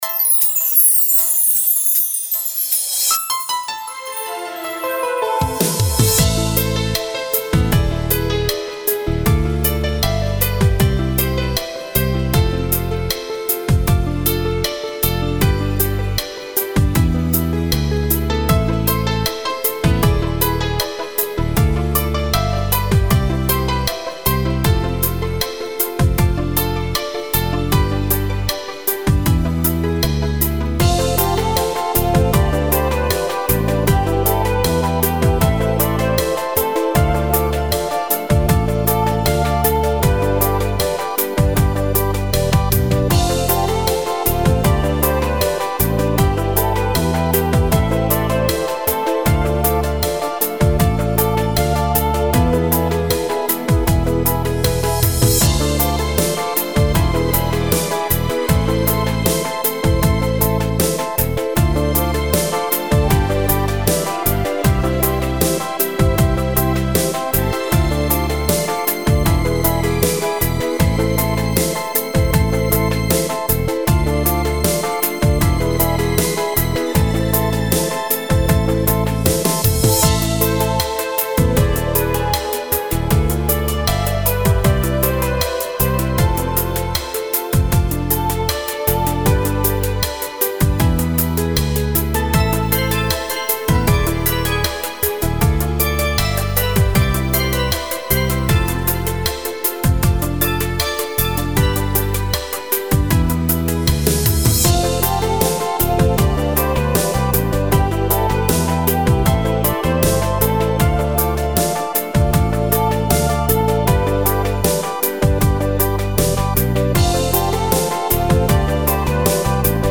Инструментальная композиция под названием "Долгожданная встреча"